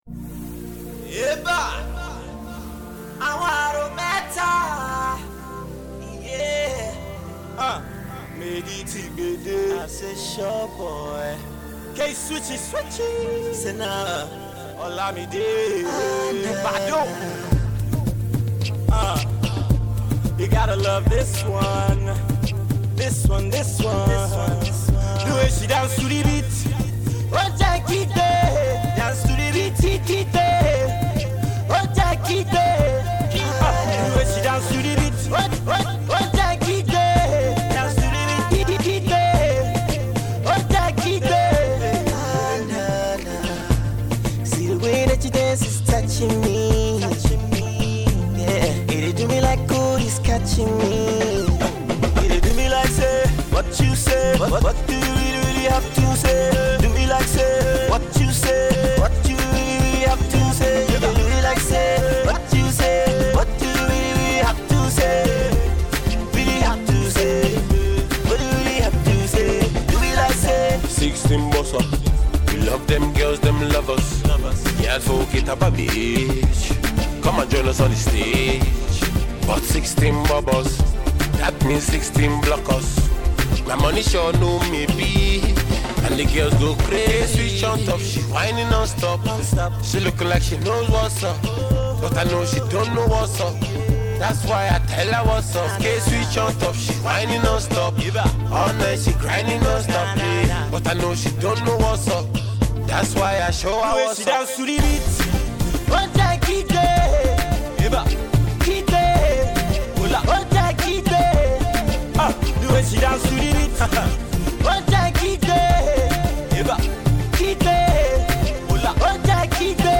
Get ready to hit the dance floor.